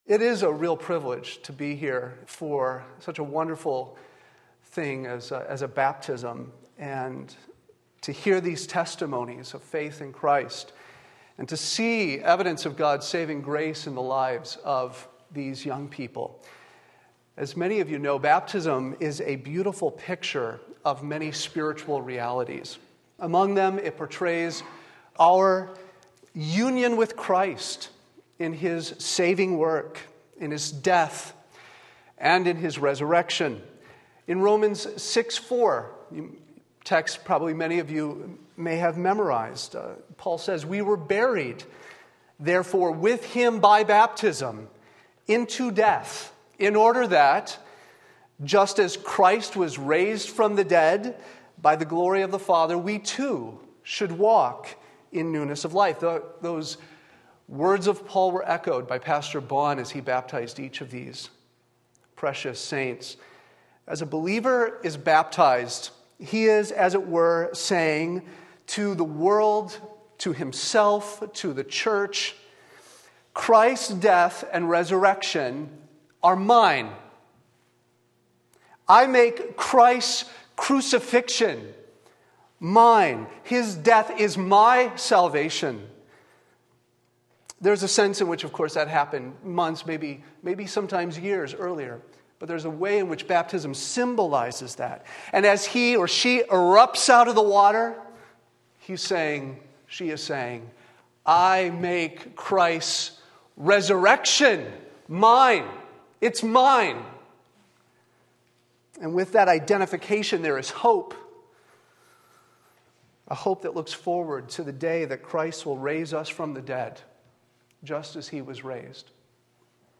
Sermon Link
Finally Victory 1 Corinthians 15:53-57 Sunday Morning Service